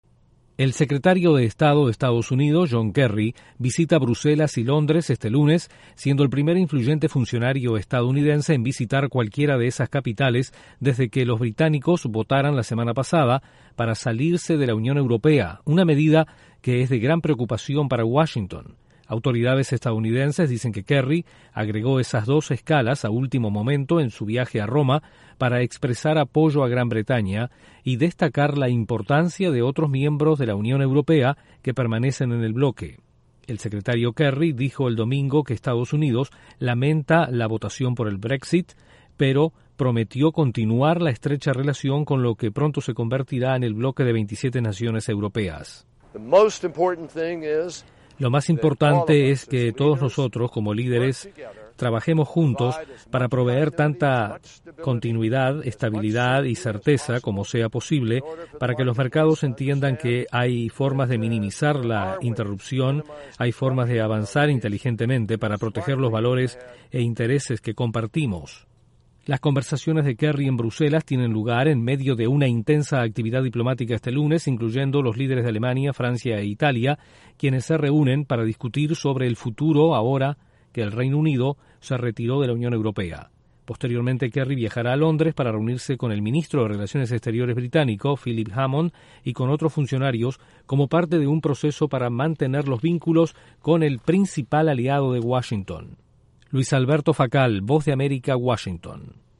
El secretario de Estado de EE.UU. visita Bruselas y Londres después que el Reino Unido votara a favor de retirarse de la Unión Europea. Desde la Voz de América en Washington informa